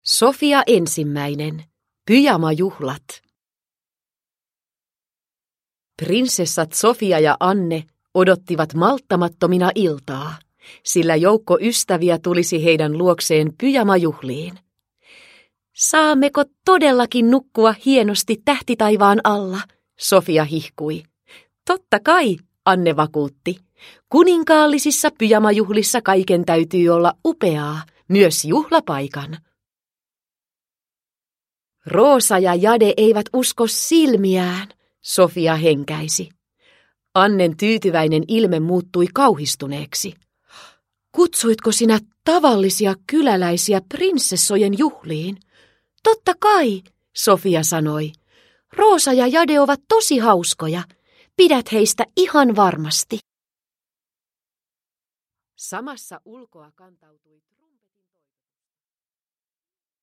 Sofia ensimmäinen. Pyjamajuhlat – Ljudbok – Laddas ner